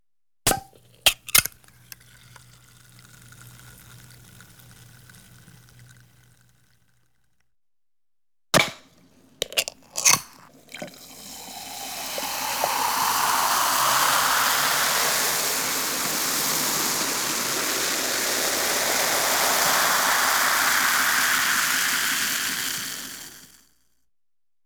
household
Can Soft Drink Open Pour